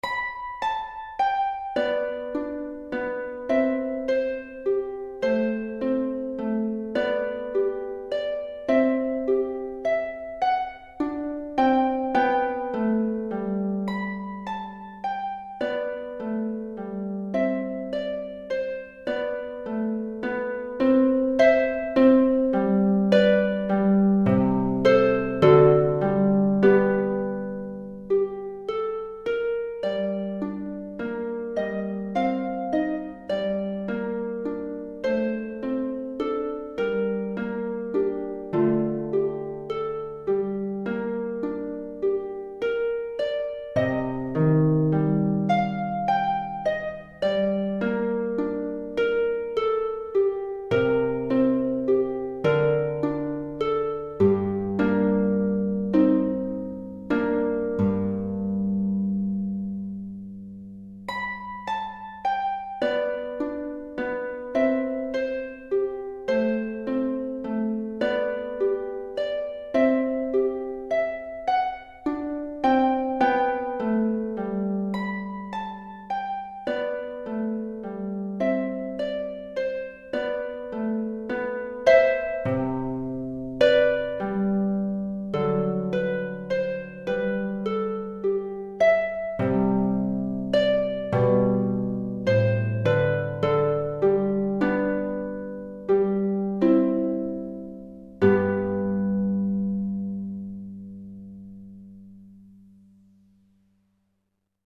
Pour harpe (ou harpe celtique) DEGRE CYCLE 1